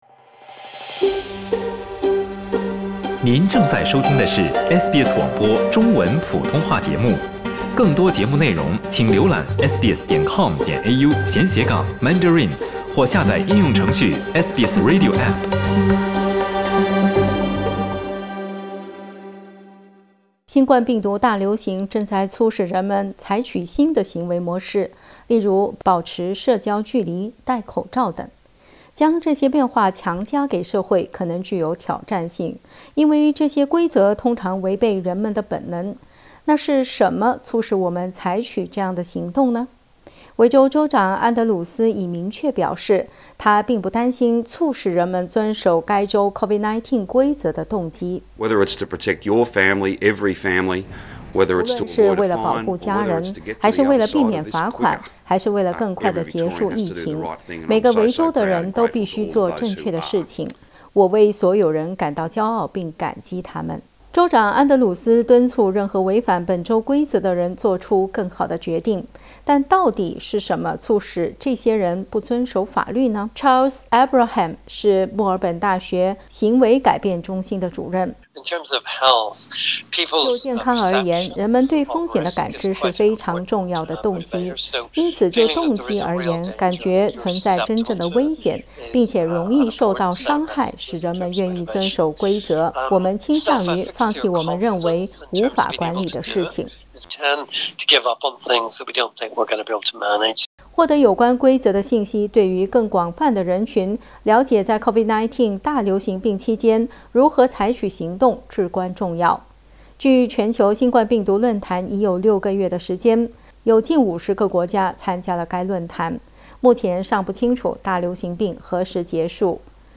点击图片收听详细报道。